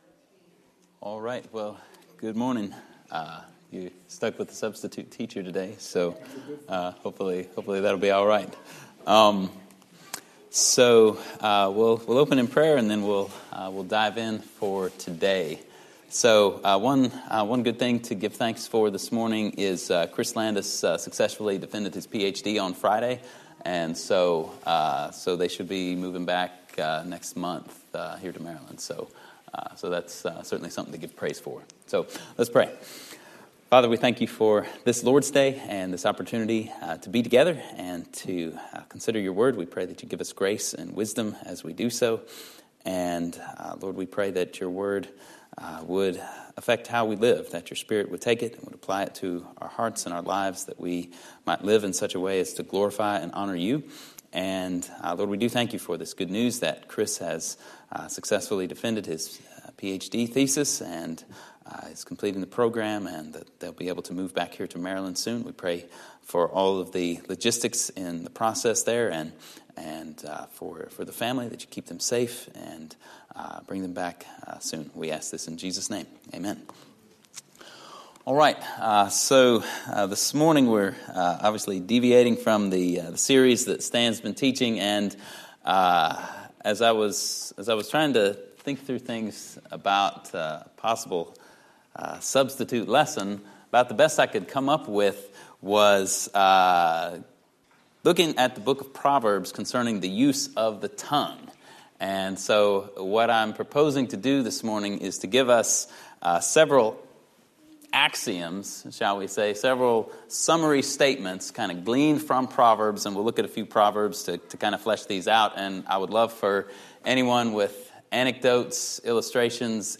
Sermons from Andover Baptist Church in Linthicum, MD.